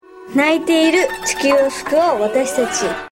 Di sini kita bisa mendengar senryu-senryu yang dibacakan.